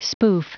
Prononciation du mot spoof en anglais (fichier audio)
Prononciation du mot : spoof